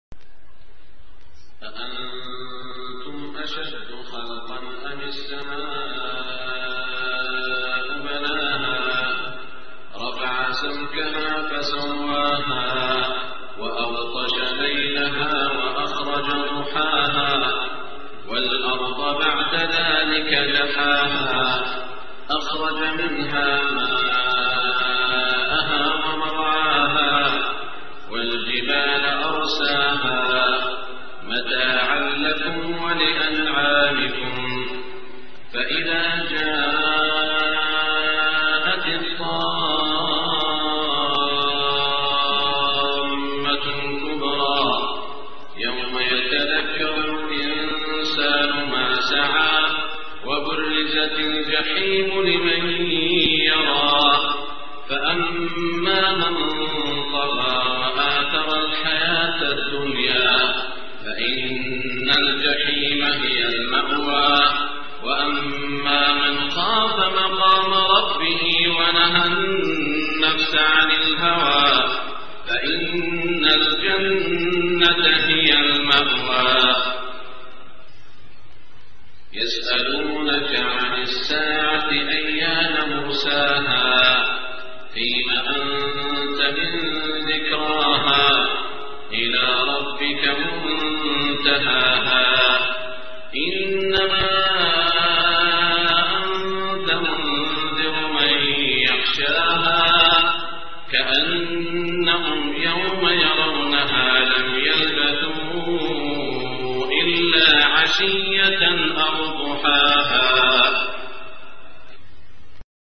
صلاة العشاء 2-1426 من سورة النازعات.